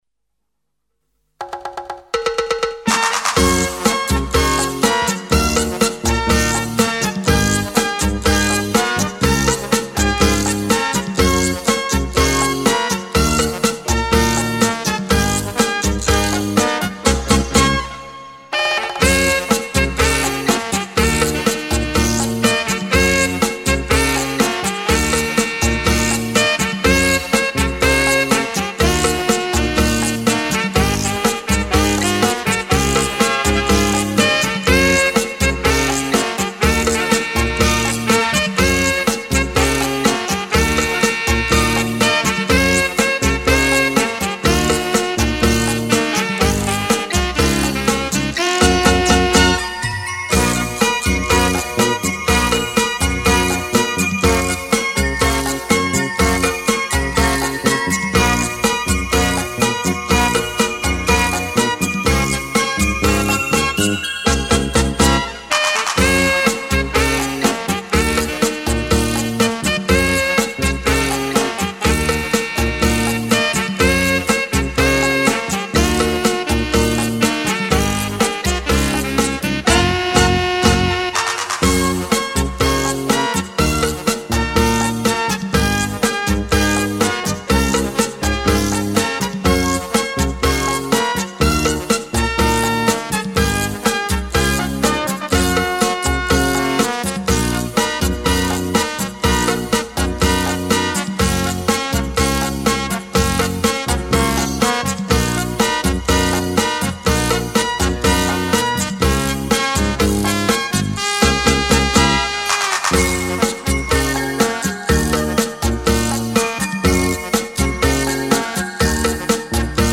恰恰  源于古巴，4/4拍子。乐曲有趣欢跃，舞步利落、紧凑、多姿；神态华俏、轻松、热烈。